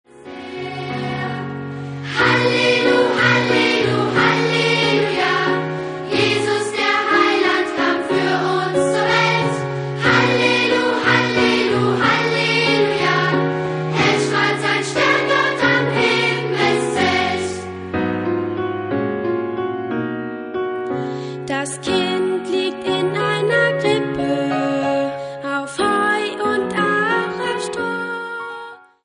Neue Advents- und Weihnachtslieder für Kinder